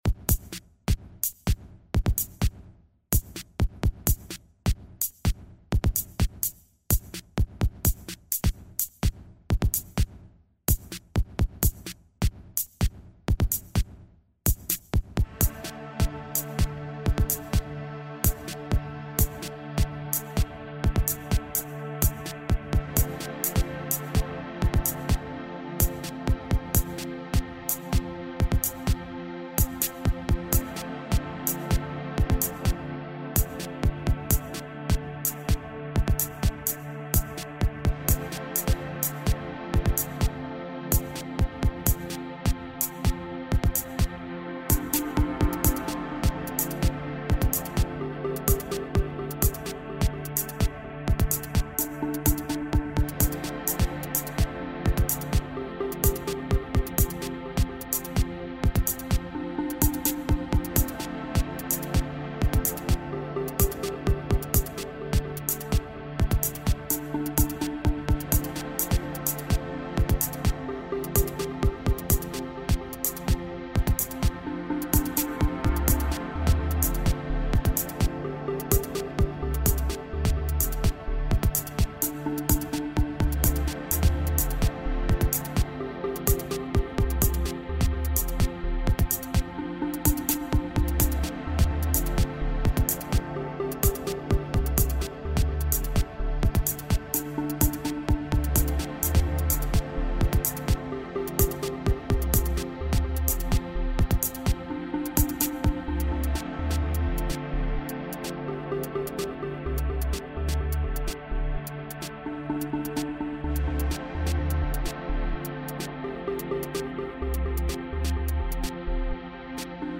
This track is odds and ends.
This is the last 130bpm style track ive ever made.